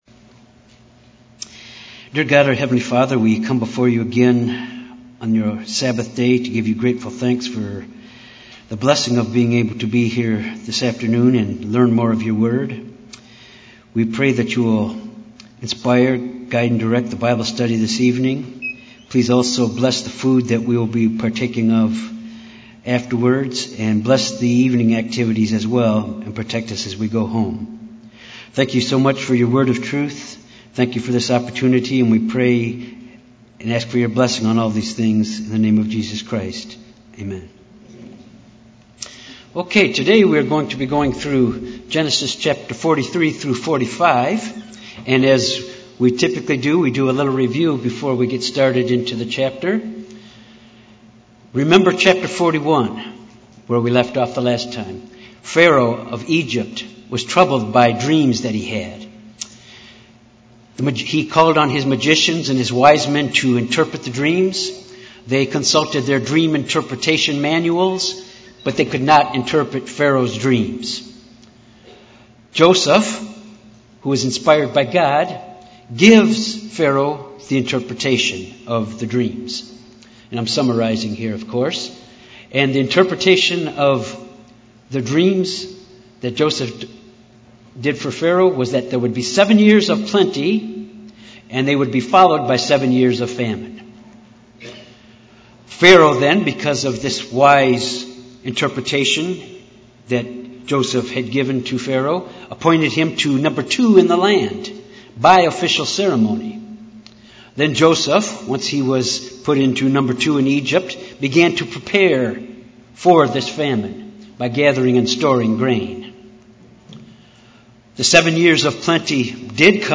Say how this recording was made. Given in Little Rock, AR